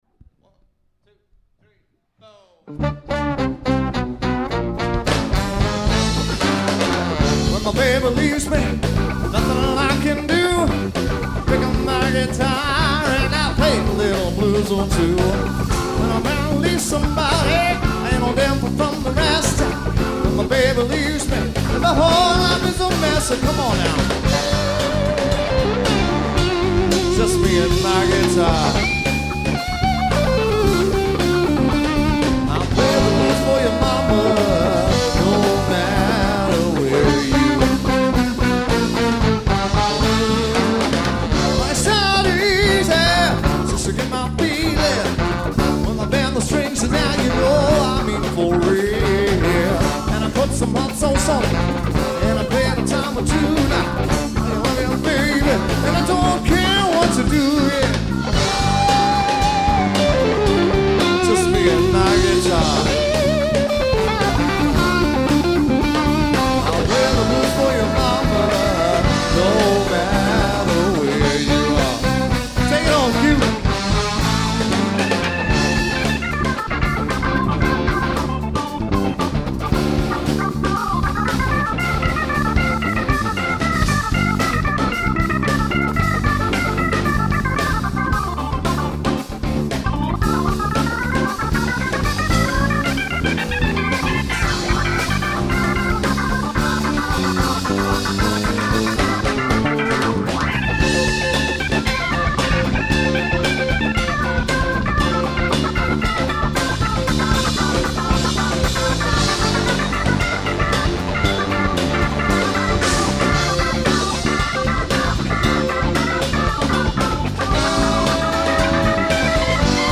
Blues guitarist and vocalist